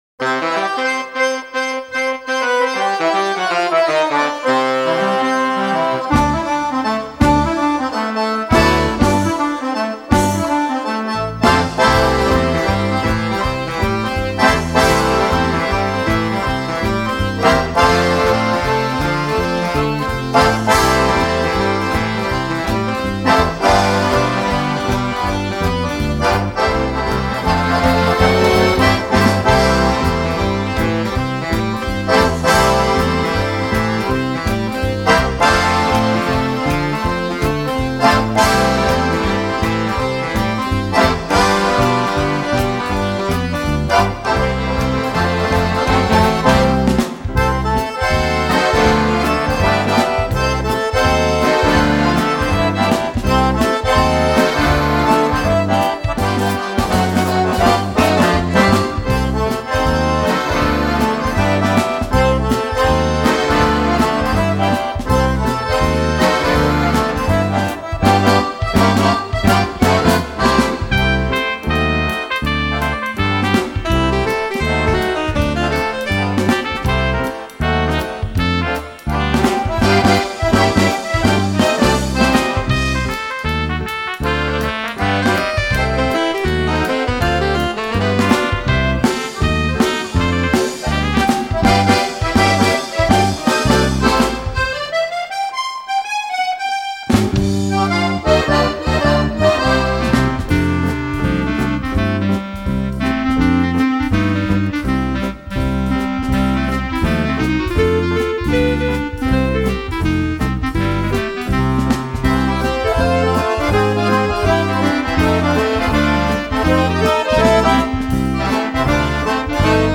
So arbeitete man von Anfang an mit Schlagzeug und E-Bass.